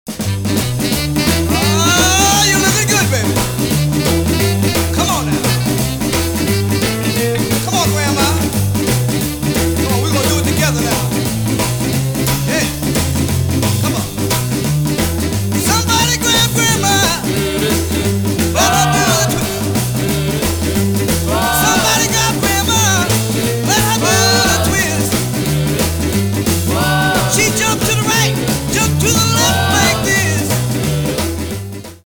sounds of the 50's and 60's!!
Great example of the "Philly R&B sound"!
Beautiful dance tunes of various Philly artists!